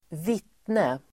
Uttal: [²v'it:ne]